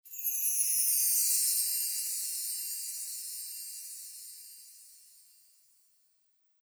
SFX – CHIME – F
SFX-CHIME-F.mp3